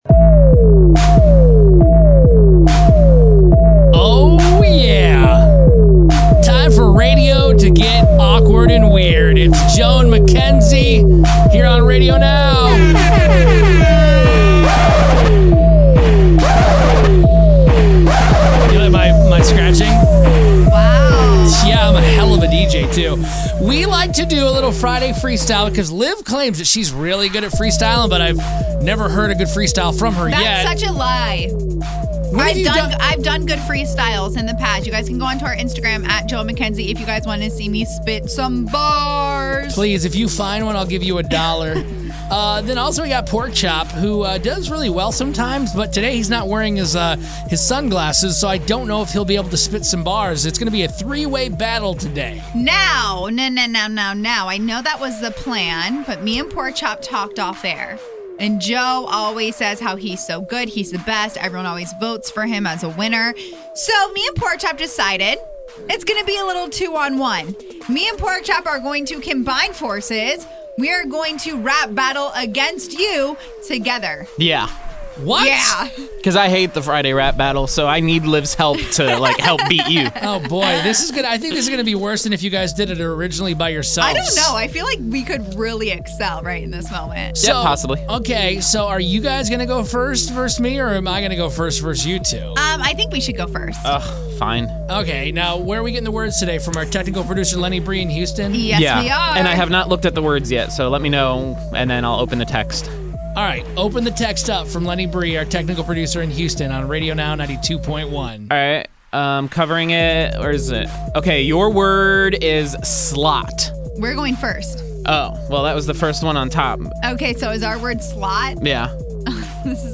Friday Freestyle Rap Battle